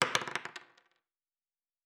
Dice Single 10.wav